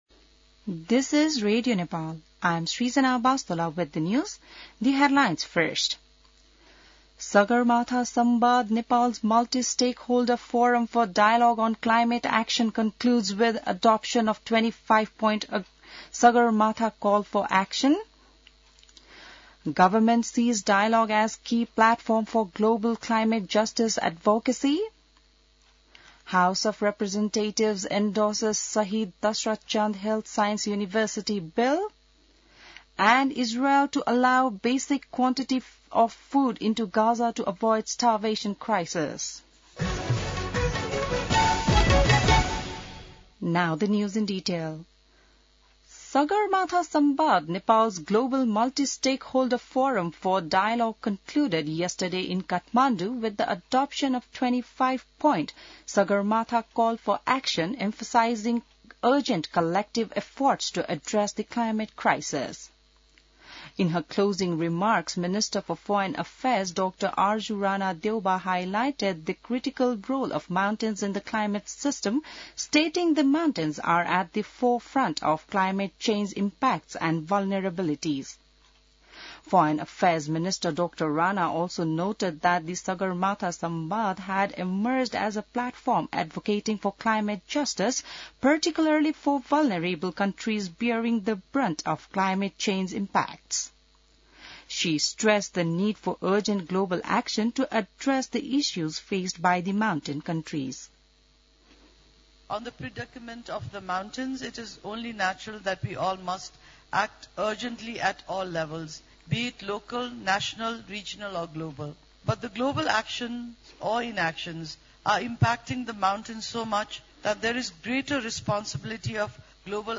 An online outlet of Nepal's national radio broadcaster
बिहान ८ बजेको अङ्ग्रेजी समाचार : ५ जेठ , २०८२